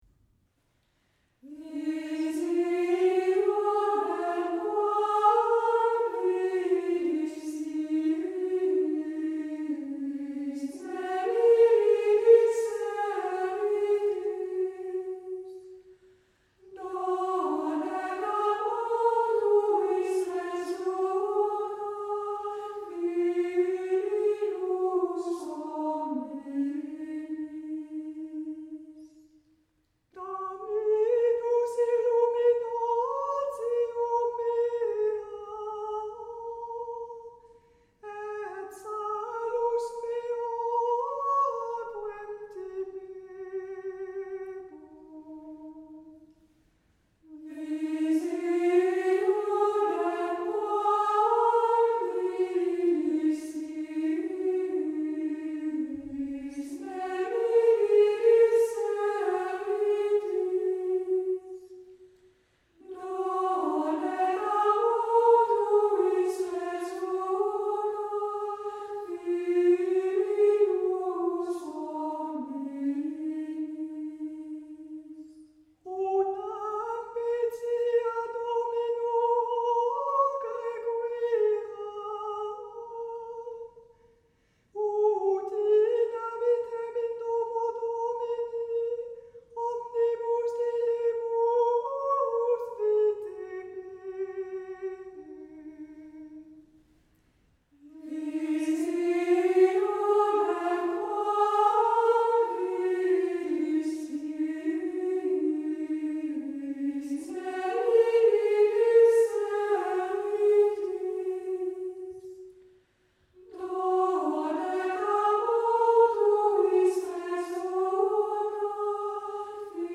14-Communio-Visionem-Quam-Vidistis.mp3